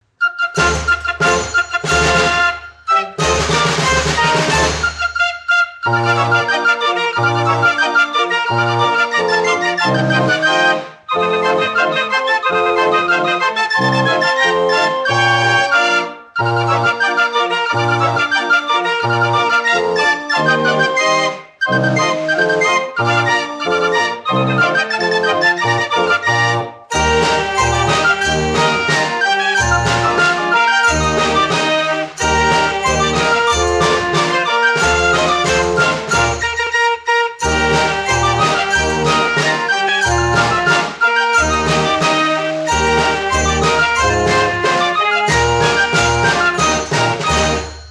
BAND ORGANS/FAIR ORGANS